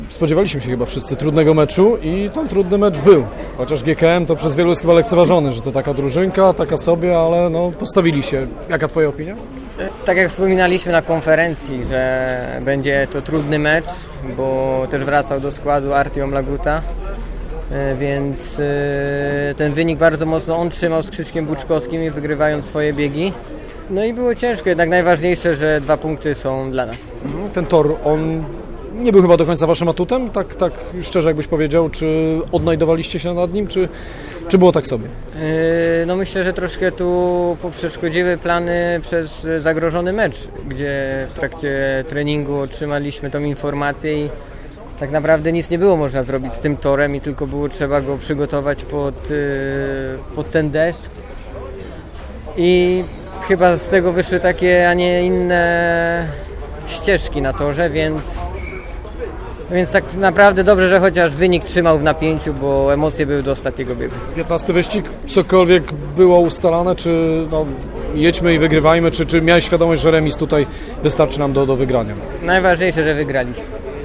Posłuchajmy, co mówił Patryk Dudek: